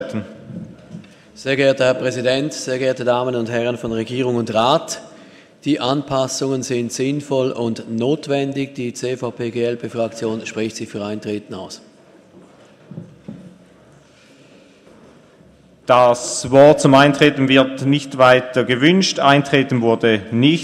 20.9.2017Wortmeldung
Session des Kantonsrates vom 18. bis 20. September 2017